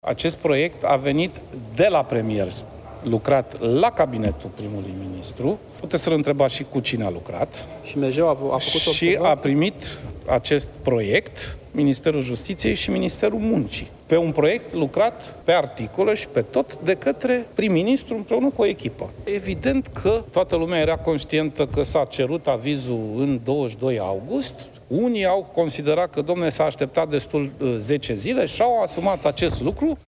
Președintele interimar al PSD, Sorin Grindeanu: „Este un proiect lucrat pe articole și în ansamblu de către prim-ministru, împreună cu o echipă”